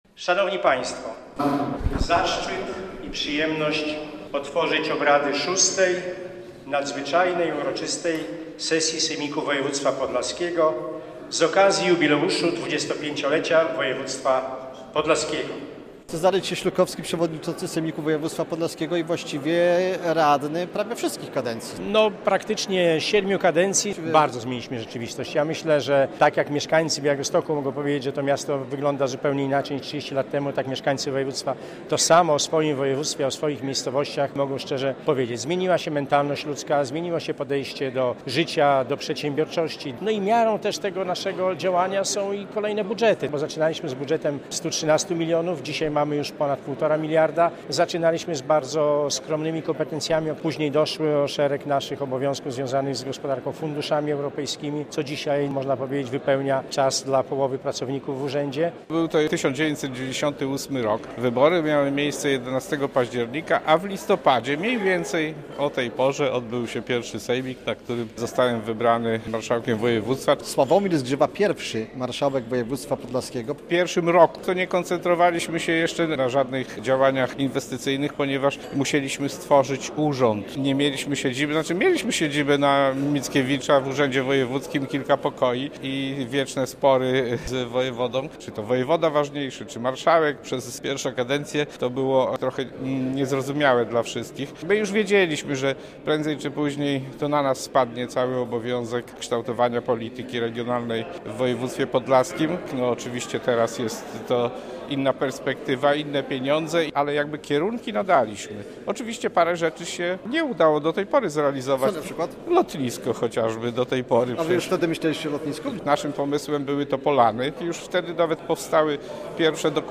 Uroczysta sesja sejmiku województwa podlaskiego z okazji 25-lecia samorządu - relacja
Gości przywitał gospodarz wydarzenia: przewodniczący sejmiku Cezary Cieślukowski.